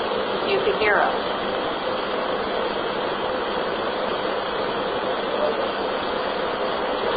That seems to be the question I was asked when I did an EVP session at a nursing home.